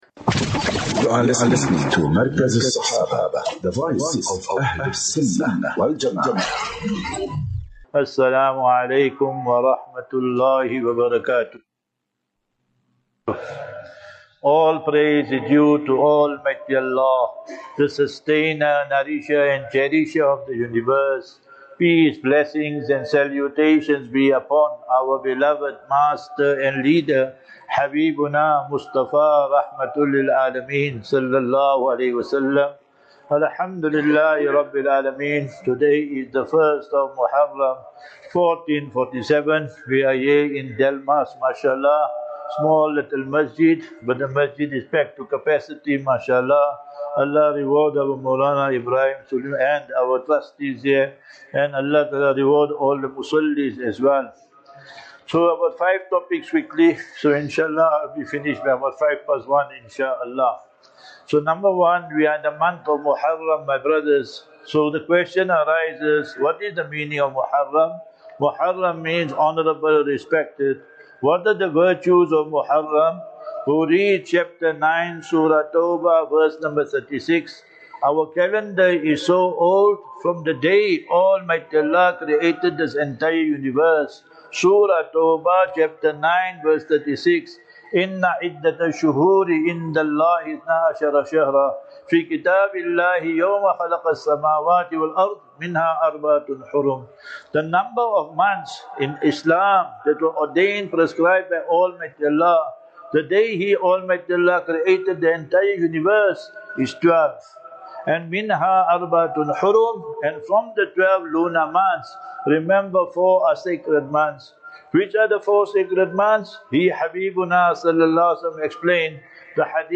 27 Jun 27 June 25 - Jumu,ah Lecture at Masjid Tauheed (Delmas, Mpumalanga).